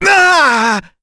Fluss-Vox_Damage_05.wav